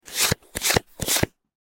جلوه های صوتی
دانلود صدای ورق زدن کارت از ساعد نیوز با لینک مستقیم و کیفیت بالا